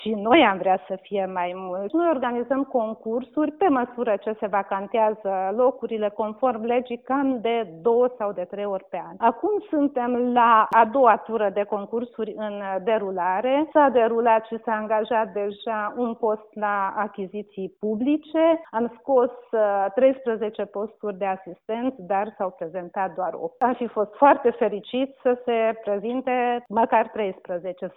a acordat astăzi un interviu pentru Radio Tg. Mureş